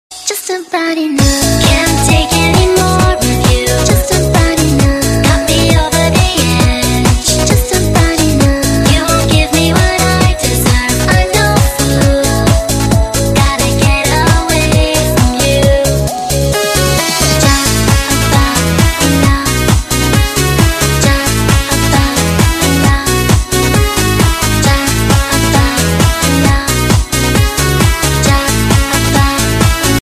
分类: DJ铃声
迷幻俱乐部 MAGIC CLUB 慢摇 DJ舞曲